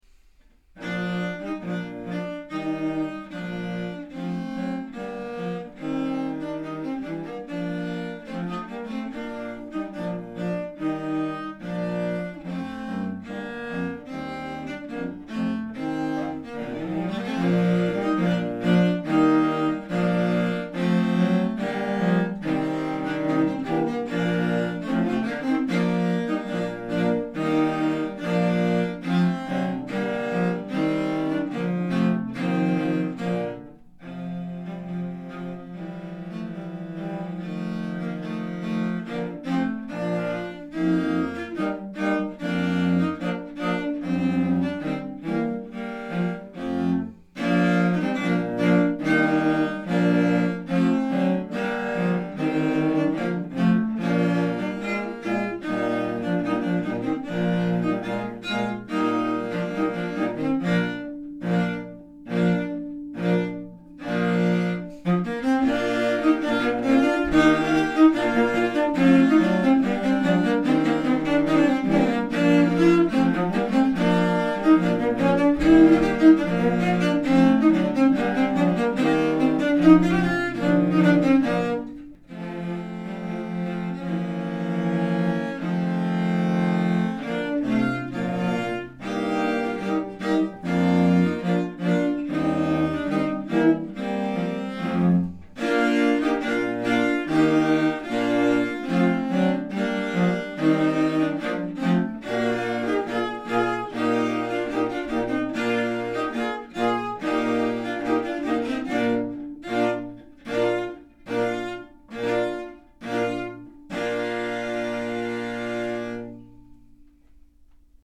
St.-Anthony-Chorale-Cello-Quartet.mp3